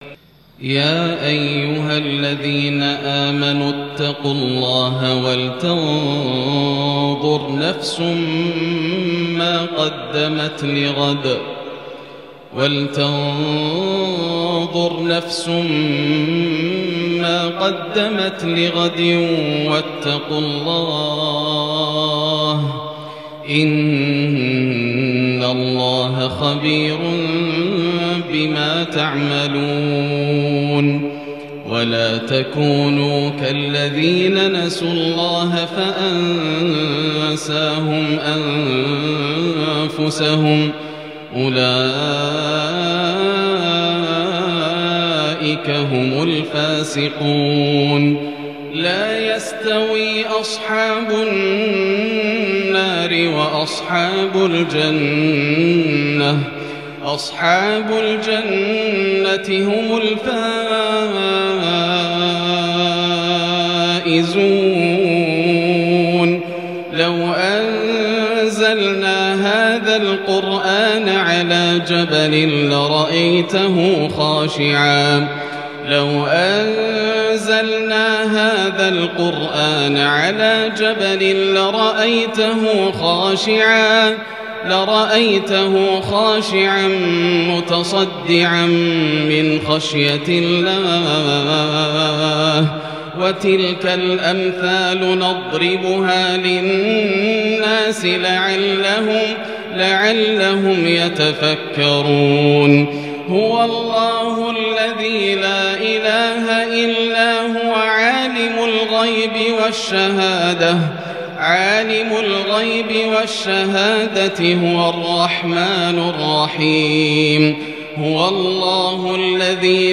صلاة المغرب ١-٤-١٤٤٢ هـ سورتي الحشر و الزلزلة Maghrib prayer Surat AlHashr-And AzZalzalah 16/11/2020 > 1442 🕋 > الفروض - تلاوات الحرمين